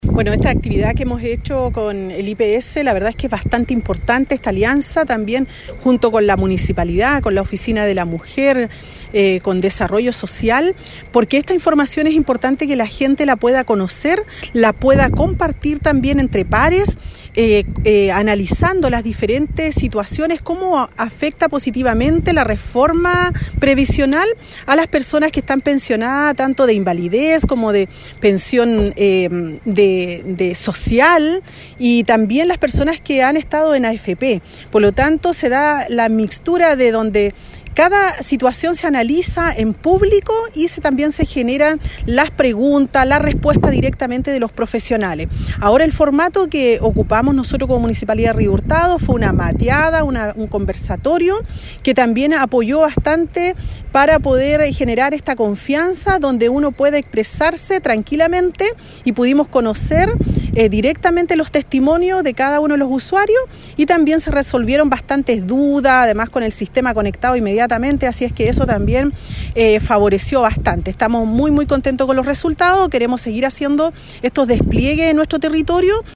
Mientras que la alcaldesa destacó que la Reforma de Pensiones se explique con cercanía a los vecinos de Río Hurtado.
Cuna-alcaldesa.mp3